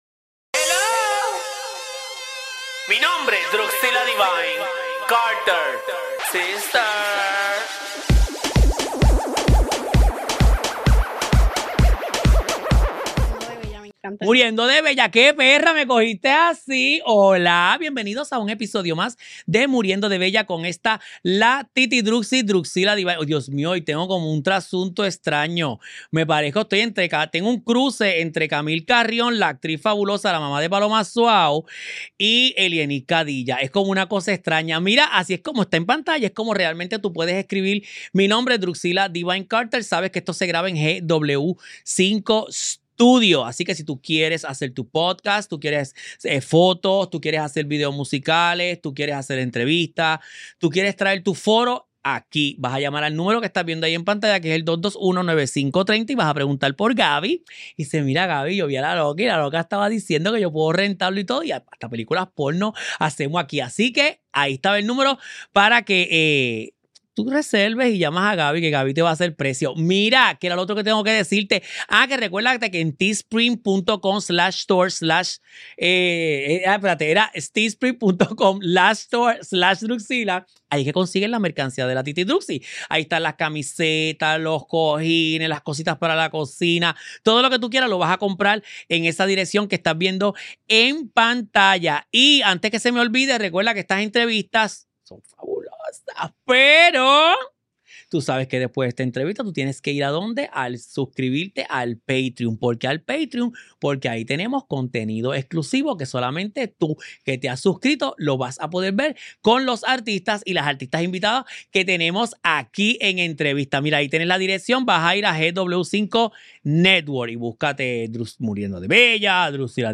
Grabado en los estudios de GW-Cinco y somos parte del GW5 Network.